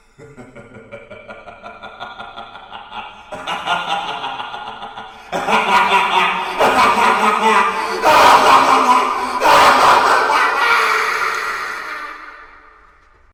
Звуки со смехом злого ученого, маньяка и его лаборатория для монтажа видео в mp3 формате.
6. Звук маньяка ученого